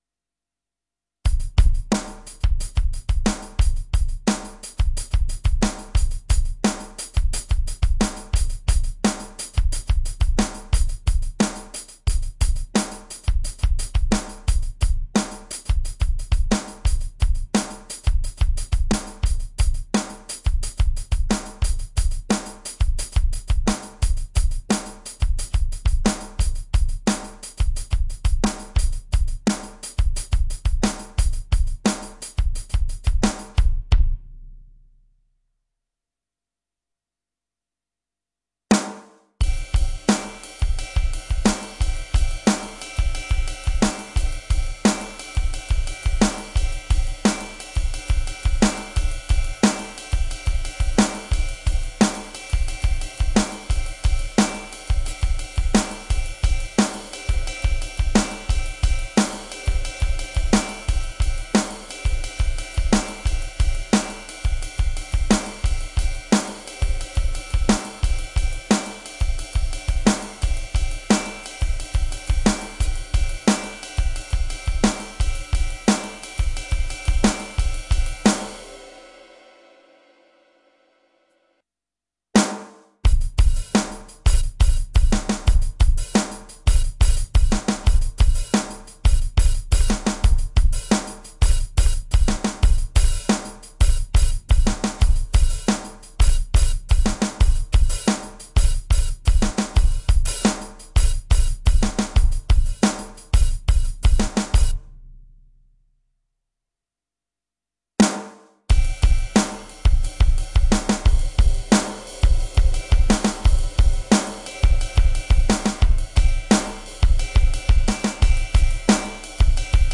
Drum loop " Rock Funk drum loops 88 bpm
描述：放克摇滚风格的鼓声循环，4/4的签名，8的7的cicle
Tag: 放克 循环